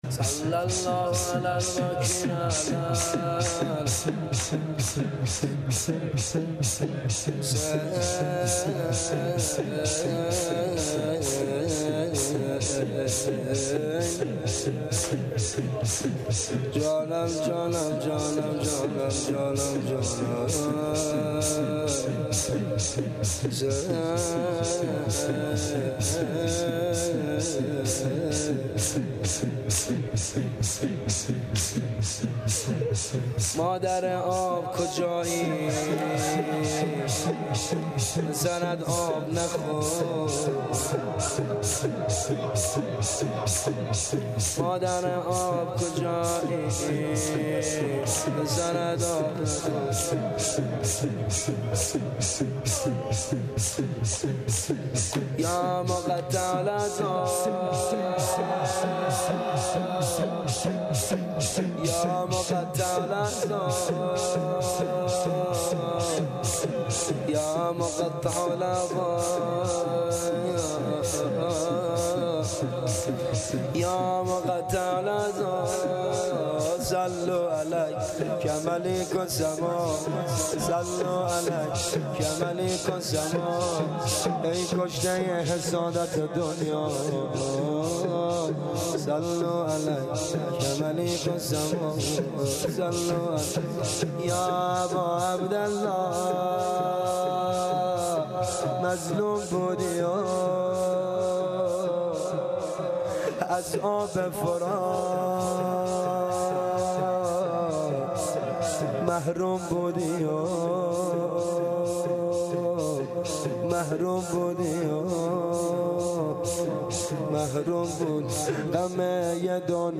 شور لطمه زنی